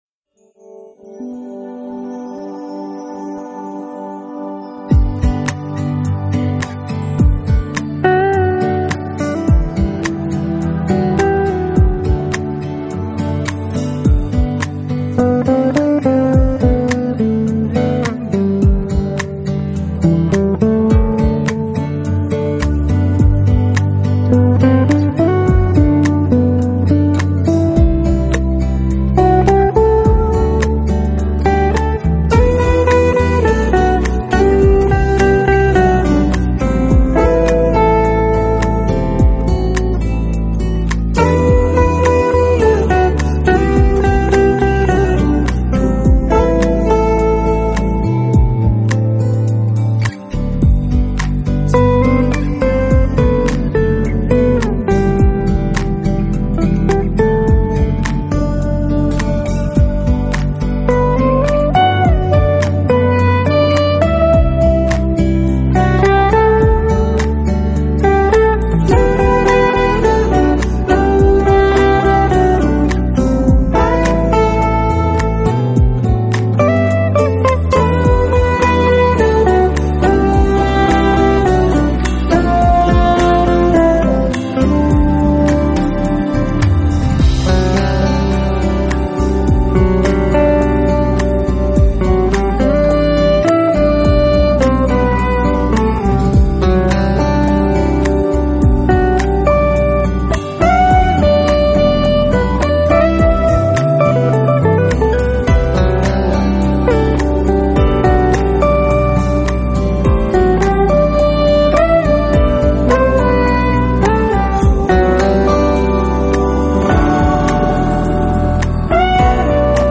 音乐类型：Smooth Jazz
两位爵士吉他大师的精心合作，音色干净透明，特
keyboards, bass guitar, programming);
(flute, saxophone);
(drums).
Recording information: Bass Hit, New York, NY.